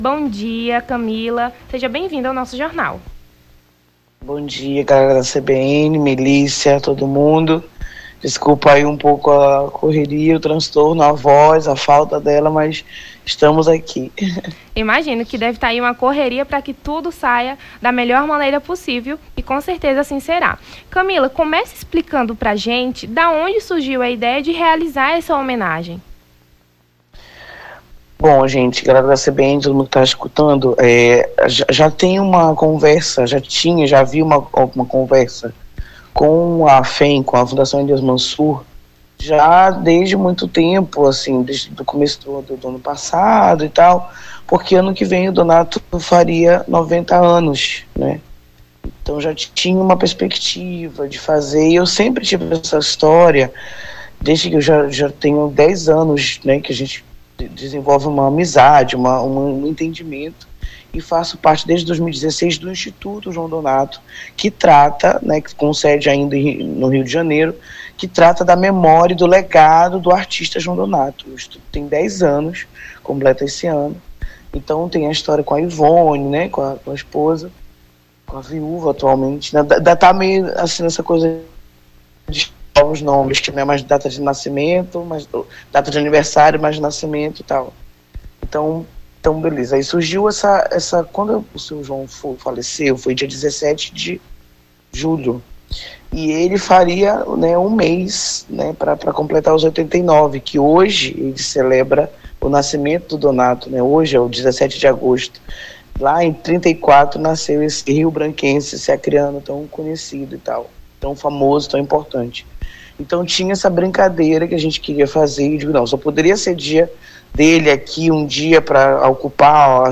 Nome do Artista - CENSURA - ENTREVISTA (DIA DO NATO) 17-08-23.mp3